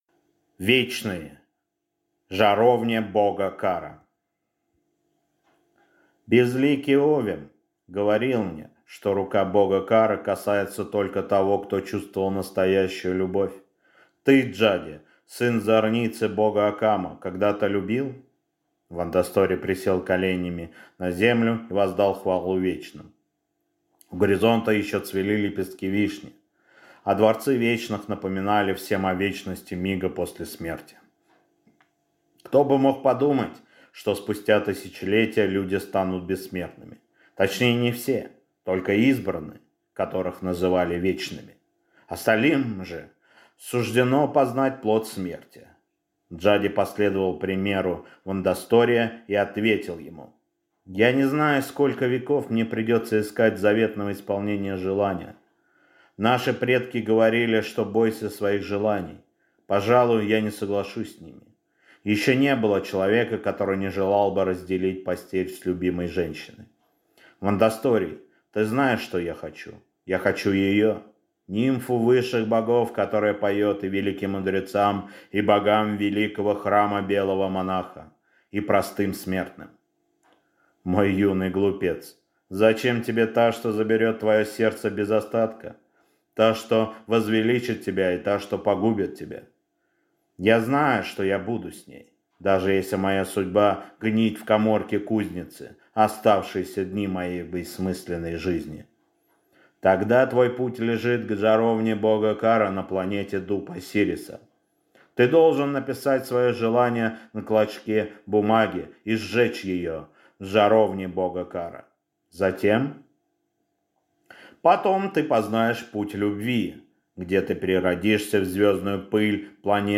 Аудиокнига Вечные. Жаровня Бога Кара | Библиотека аудиокниг